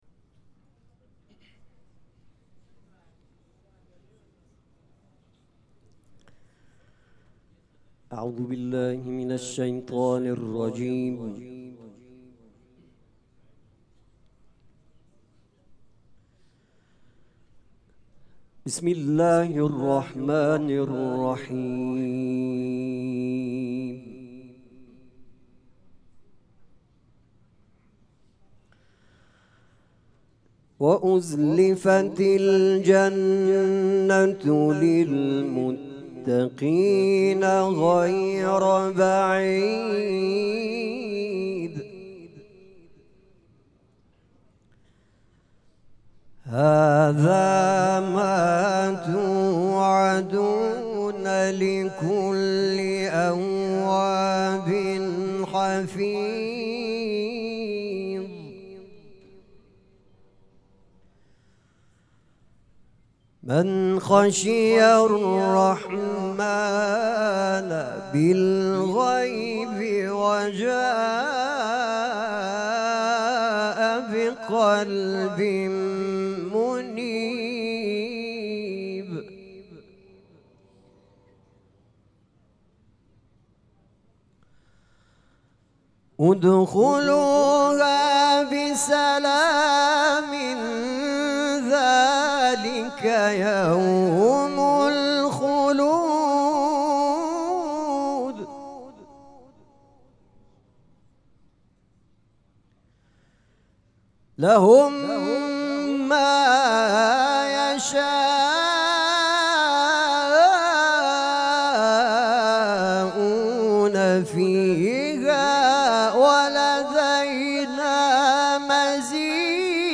مراسم عزاداری شب هفتم محرم الحرام ۱۴۴۷
قرائت قرآن